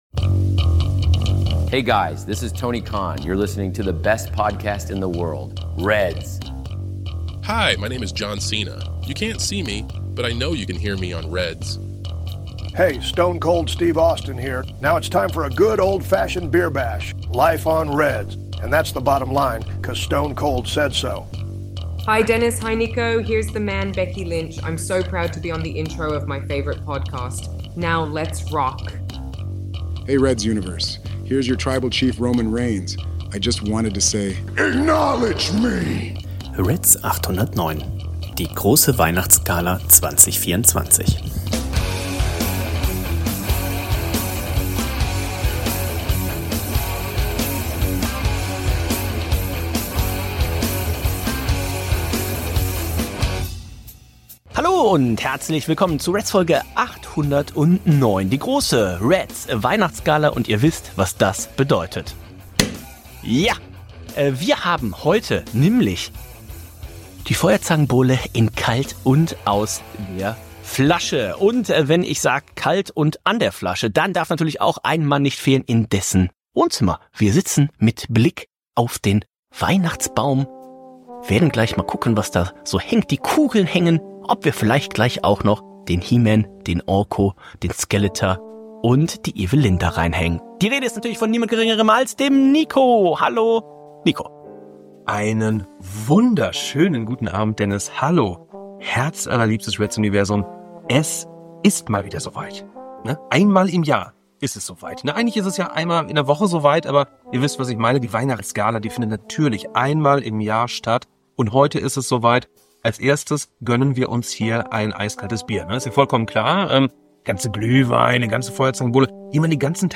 1 SmackDown 🔵 3 Stunden Spaß, Action und gute Laune – WWE Wrestling Review 03.01.2025 1:25:20